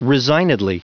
Prononciation du mot resignedly en anglais (fichier audio)
resignedly.wav